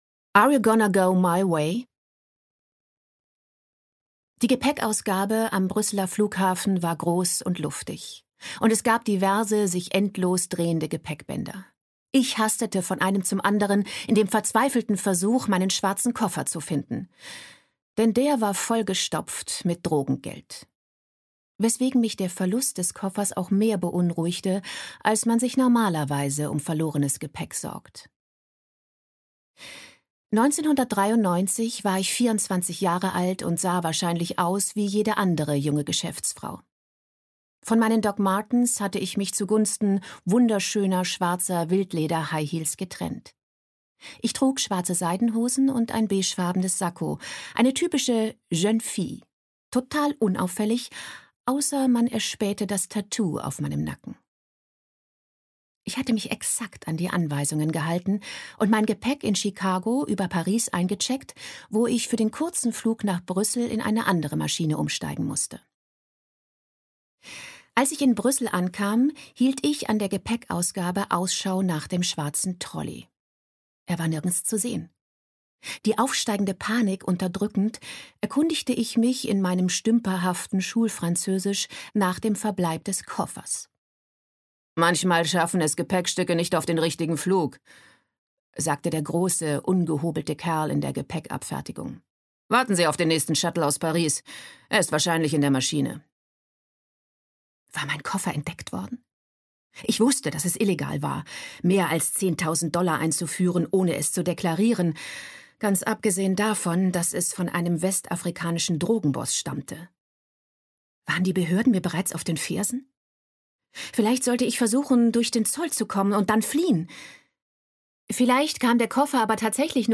Orange Is the New Black - Piper Kerman - Hörbuch